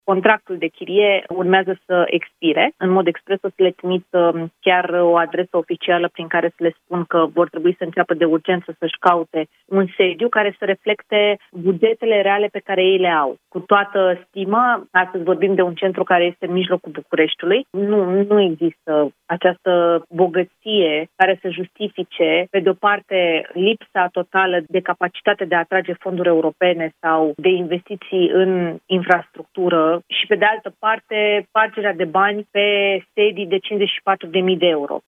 Ministrul Mediului a spus, la Europa FM că astăzi va cere Administrației Naționale Apele Române să își găsească un sediu nou, la un preț rezonabil